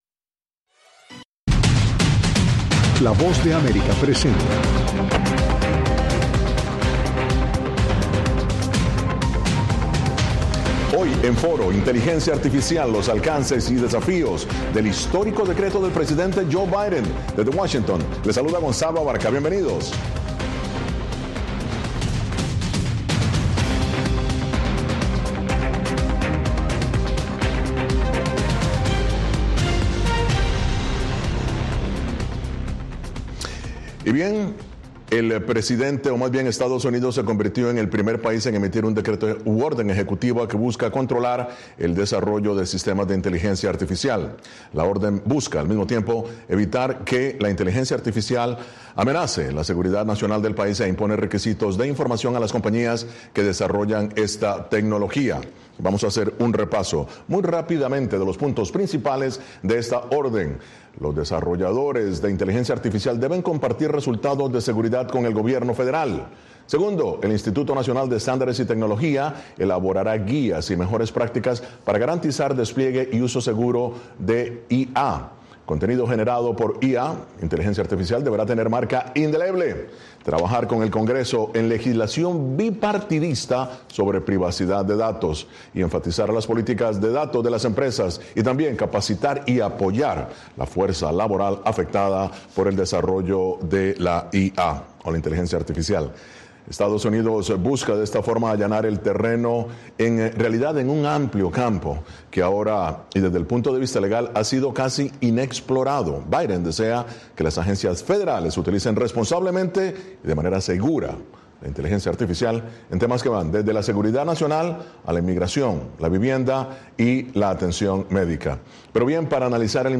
Foro (Radio): Inteligencia Artificial: alcances y desafíos